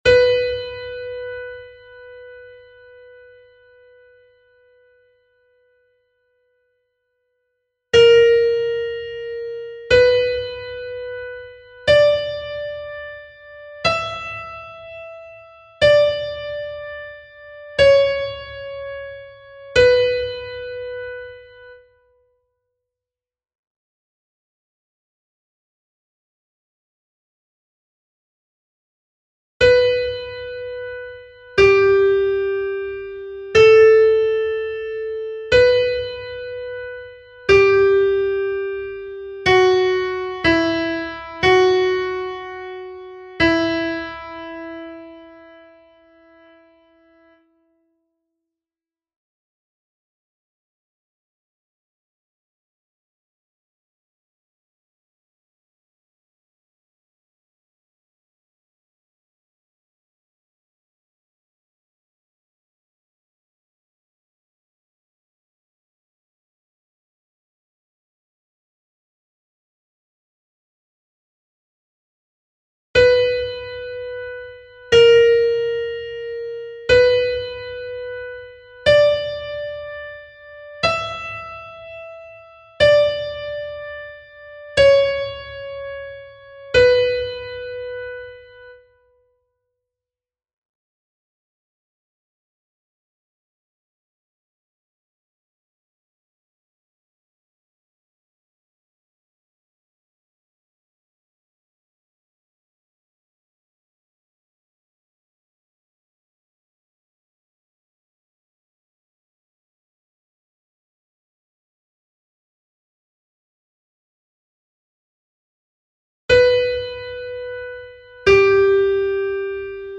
Soprano (mp3)
Au tempo 60